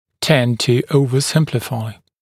[tend tu ˌəuvə’sɪmplɪfaɪ][тэнд ту ˌоувэ’симплифай]иметь тенденцию к чрезмерному упрощению